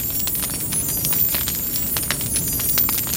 Magic_FireLoop01.wav